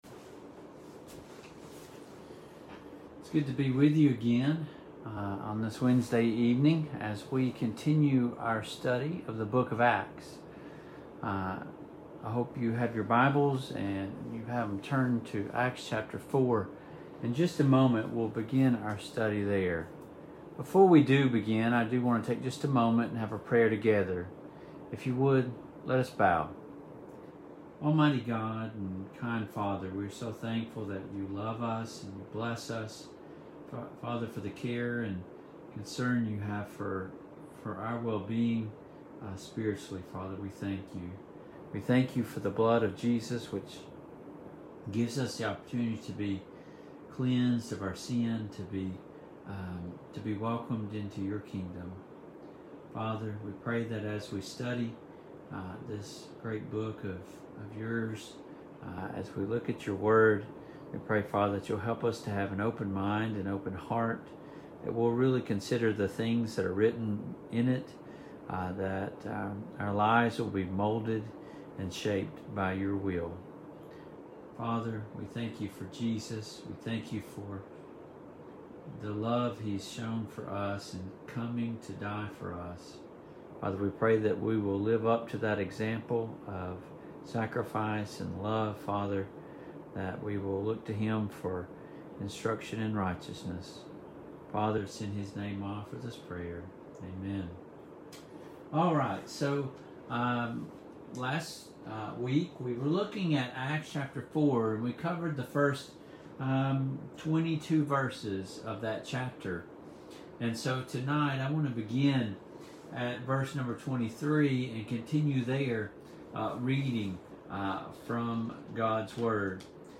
Service Type: Online Bible Studies